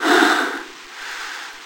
breath.ogg